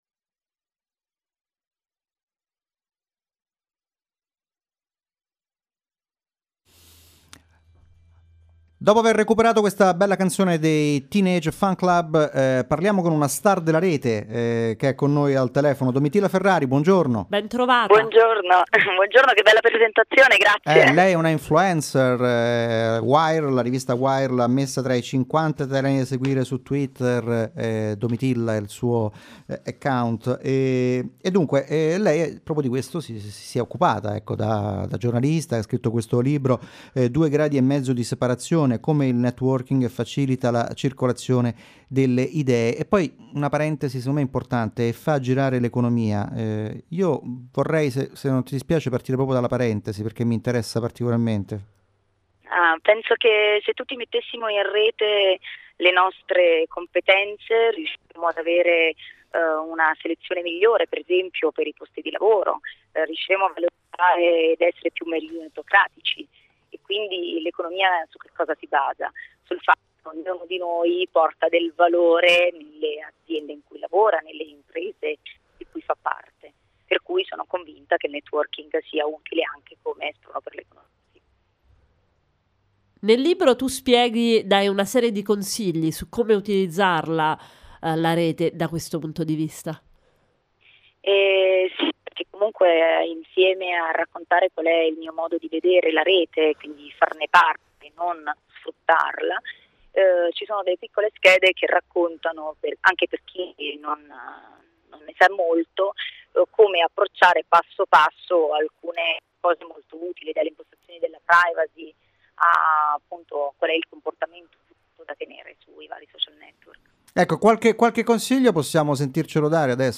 E, poi, ne ho parlato in radio: